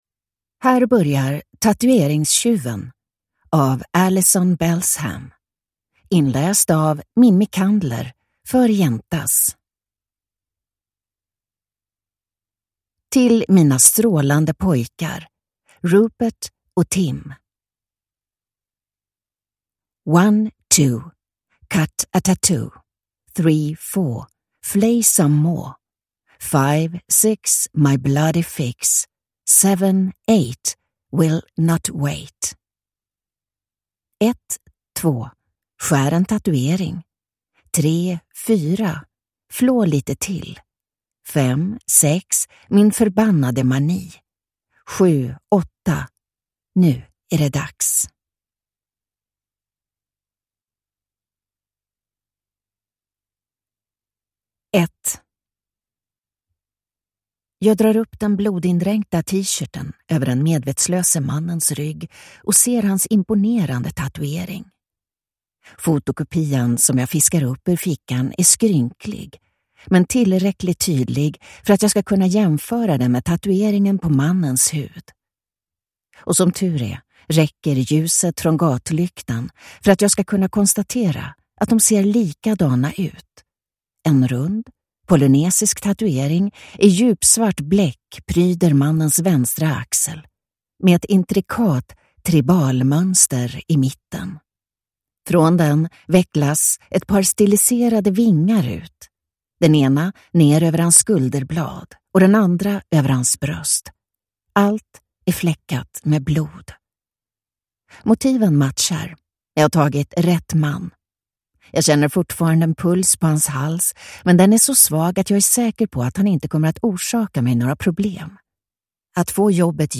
Tatueringstjuven (ljudbok) av Alison Belsham | Bokon